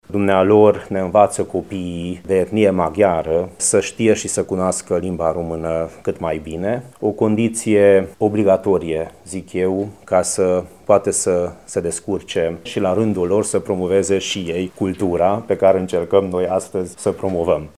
Fondatorul Studium Prosperum, deputatul Vass Levente: